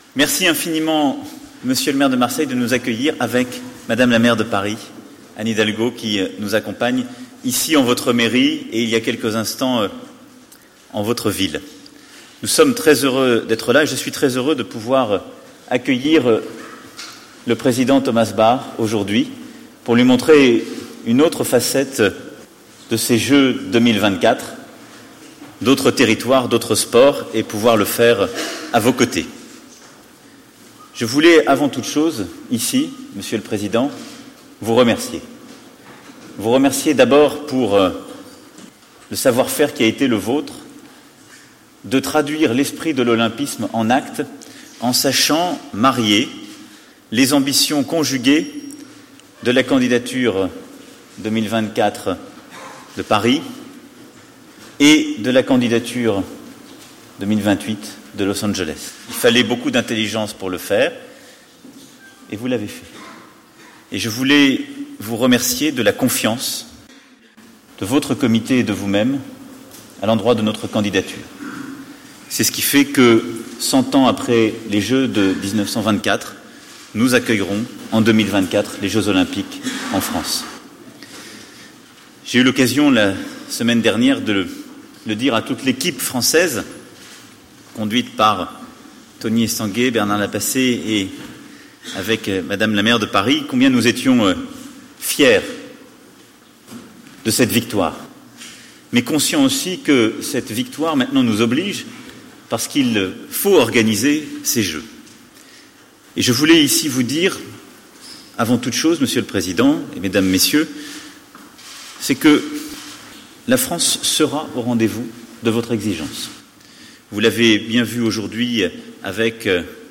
ste-053_discours_macron.mp3